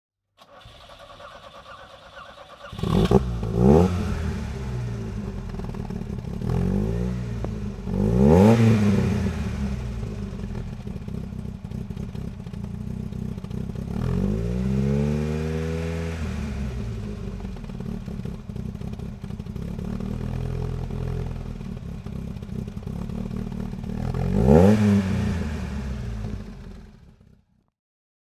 Fiat Abarth 124 Rally Gruppe 4 (1973) - Starten und Leerlauf